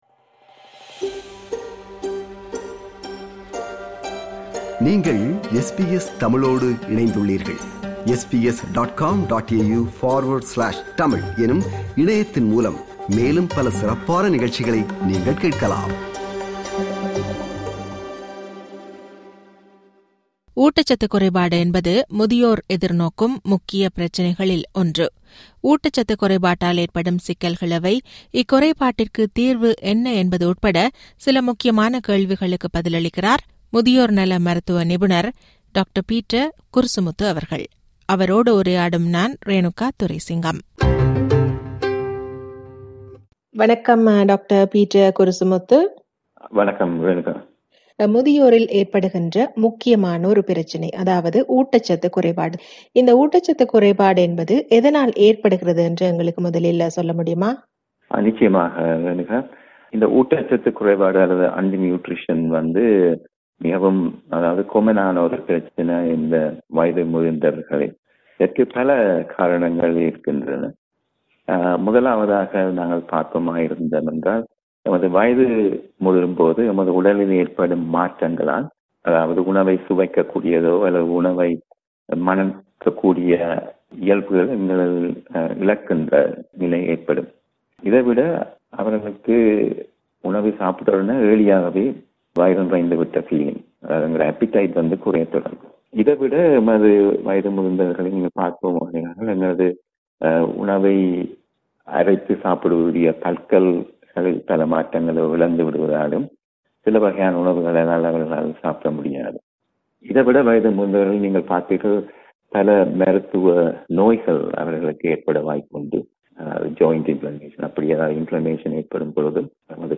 How to prevent and detect undernutrition? To hear more podcasts from SBS Tamil, subscribe to our podcast collection.